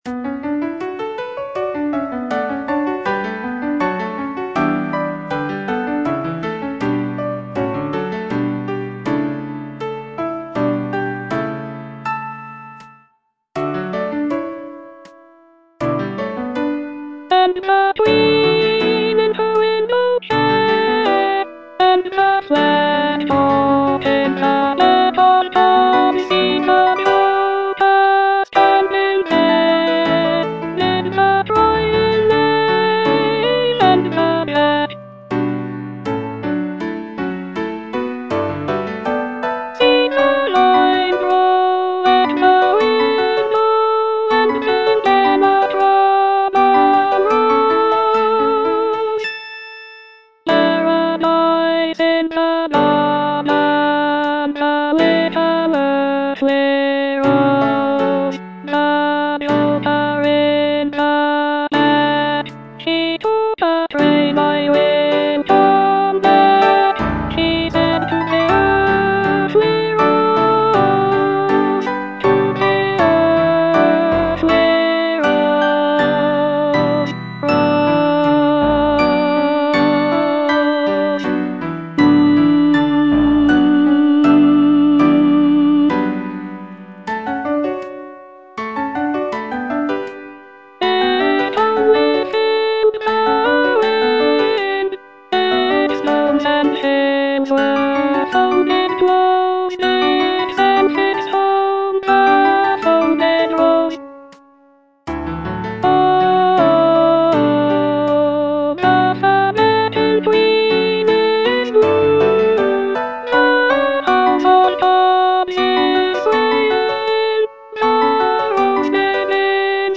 Alto (Voice with metronome)